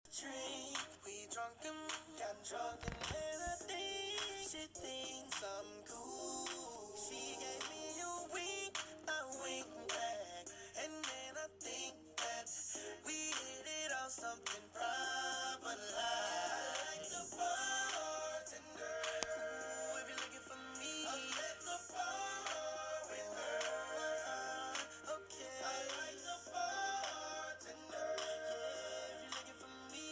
Sound : playing my favorite song on my computer